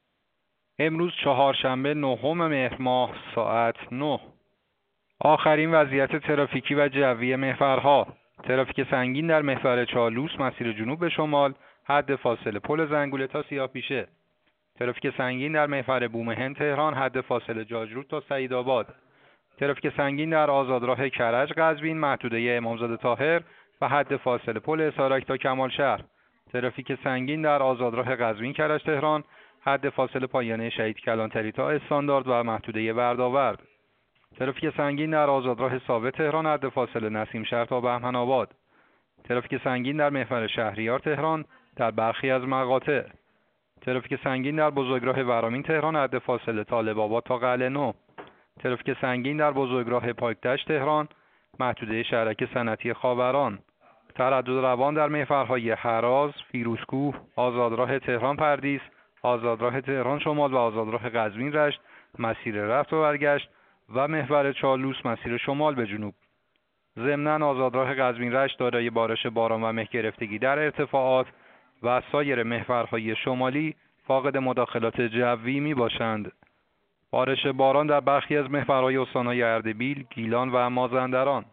گزارش رادیو اینترنتی از آخرین وضعیت ترافیکی جاده‌ها ساعت ۹ نهم مهر؛